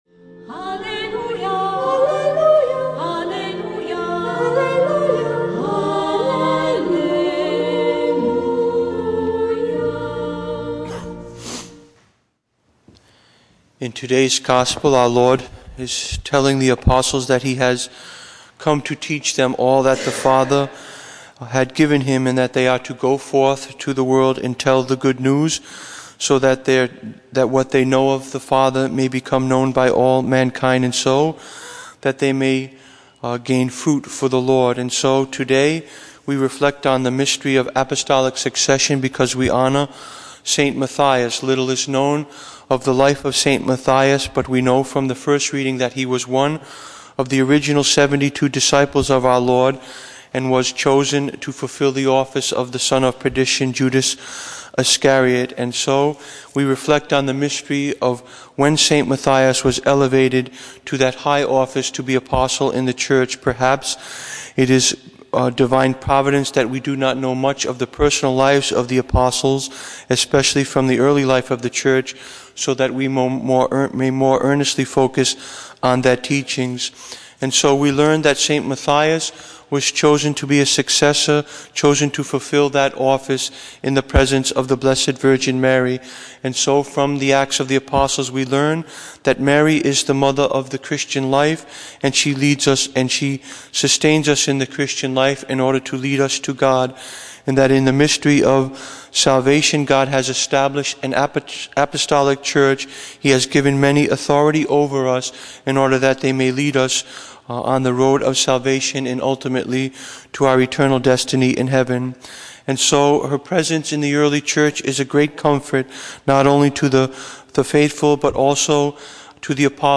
Homily: Chosen for Who He Would Become, Not Who He Was